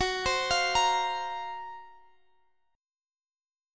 Listen to GbM7b5 strummed